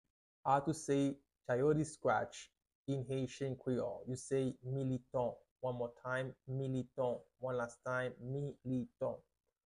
How to say “Chayote Squash” in Haitian Creole – “Militon” pronunciation by a native Haitian Teacher
“Militon” Pronunciation in Haitian Creole by a native Haitian can be heard in the audio here or in the video below:
How-to-say-Chayote-Squash-in-Haitian-Creole-–-Militon-pronunciation-by-a-native-Haitian-Teacher.mp3